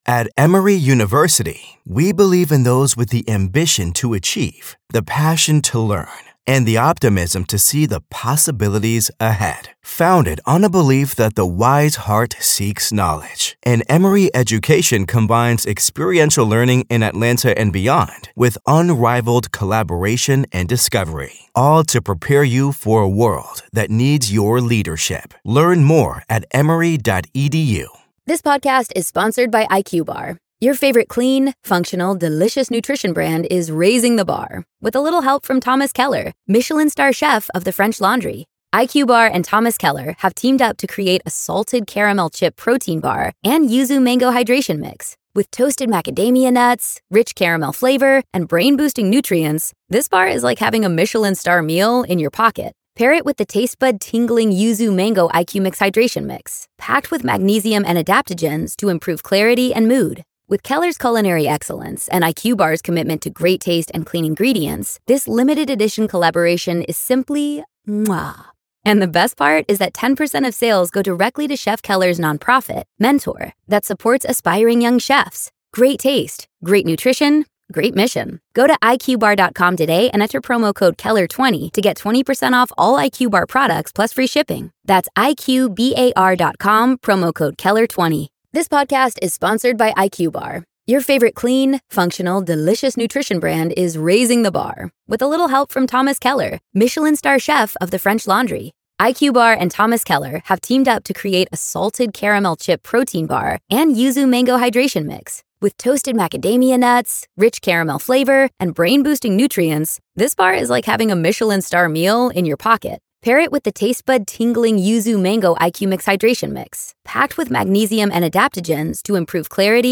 True Crime News & Commentary / Damn Damning DNA That Will Destroy Rex Heuermann